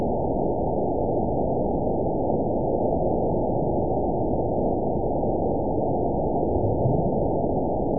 event 920422 date 03/24/24 time 16:45:58 GMT (1 year, 1 month ago) score 9.48 location TSS-AB02 detected by nrw target species NRW annotations +NRW Spectrogram: Frequency (kHz) vs. Time (s) audio not available .wav